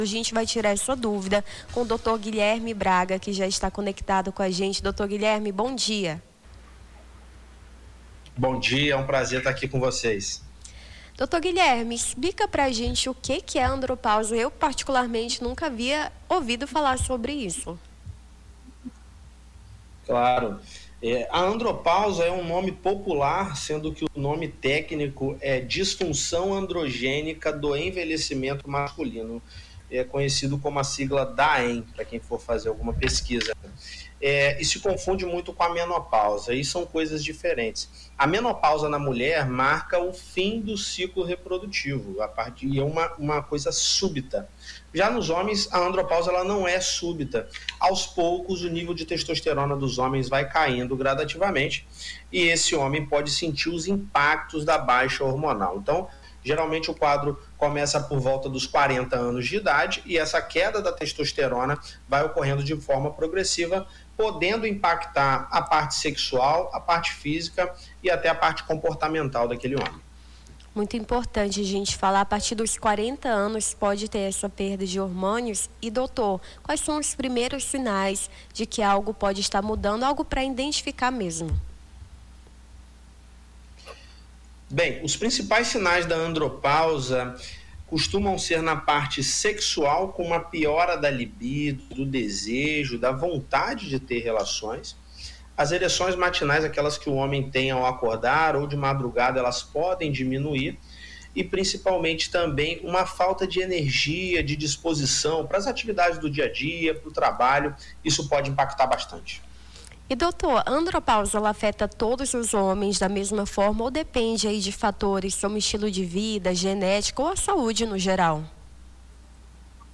Nome do Artista - CENSURA - ENTREVISTA (ANDROPAUSA) 22-10-25.mp3